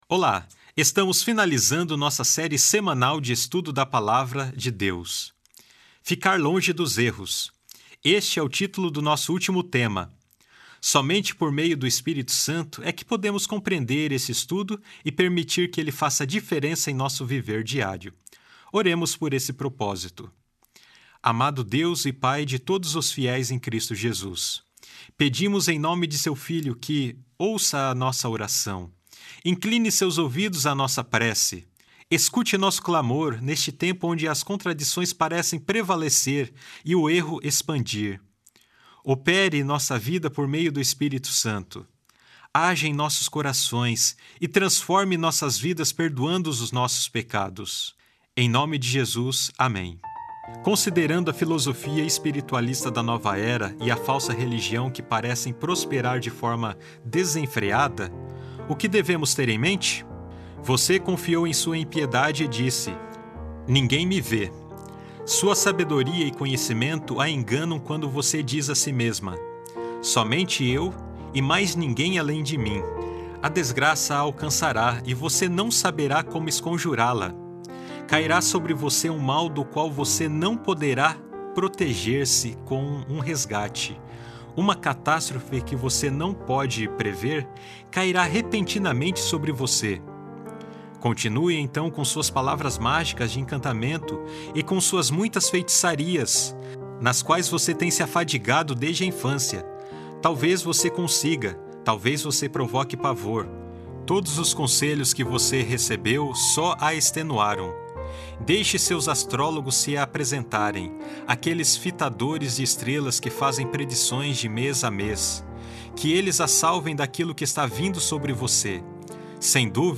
Áudios - Lição em Áudio